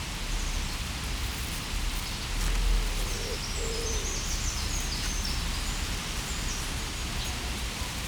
BambooGardenAtmos.wav